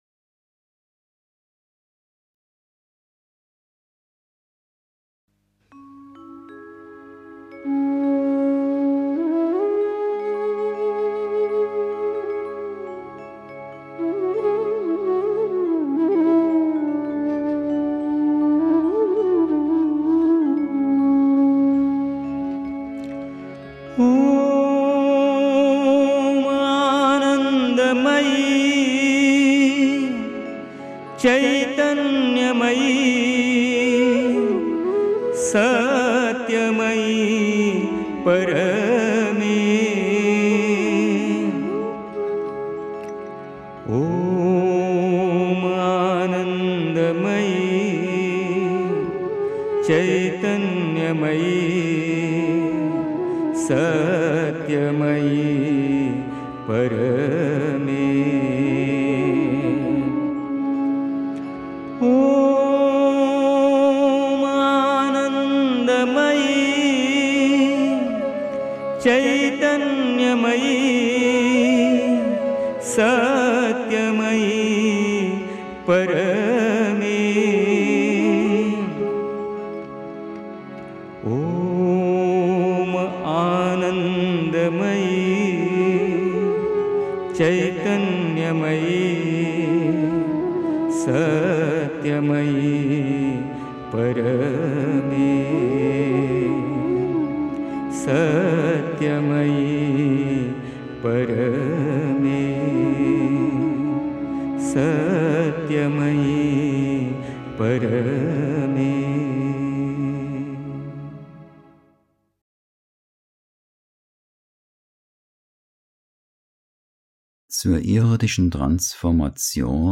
1. Einstimmung mit Musik. 2. Irdische Transformation und Harmonisierung (Die Mutter, CWMCE Vol. 2, pp. 47-48) 3. Zwölf Minuten Stille.